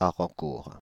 Arrancourt (French pronunciation: [aʁɑ̃kuʁ]